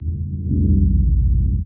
power_drone.wav